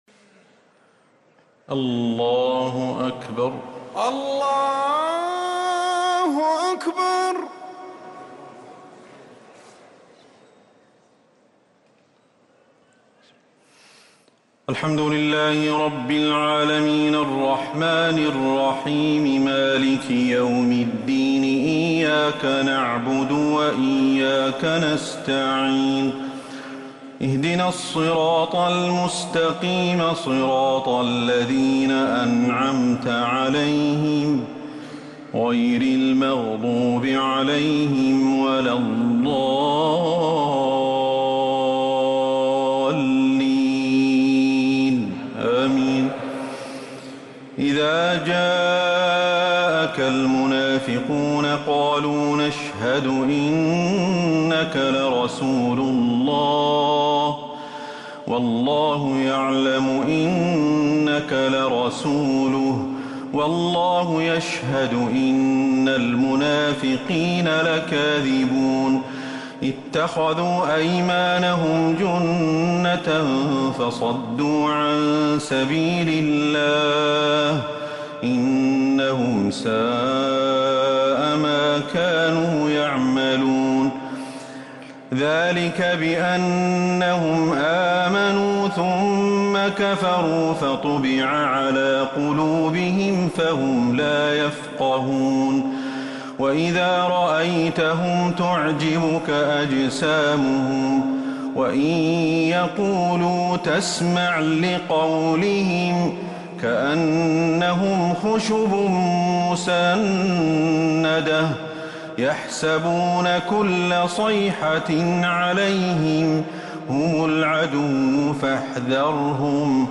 تراويح ليلة 28 رمضان 1444هـ من سورة المنافقون إلى سورة التحريم